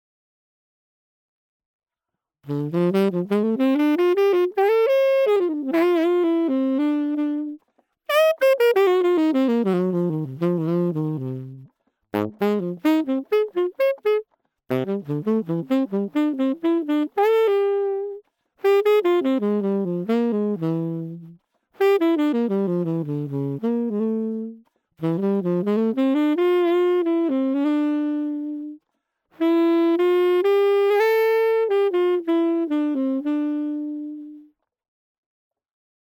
Der Tonabnehmer wurde in die Mundstücke von folgenden Instrumenten eingebaut: Bb-Klarinette und Bass-Klarinette, Sopran-, Tenor- und Baritonsaxophon sowie Bass-Saxophon und Tubax.
Tenorsaxophon mit Tonabnehmer WP-1X
Pickup_Tenor_Sounddemo.mp3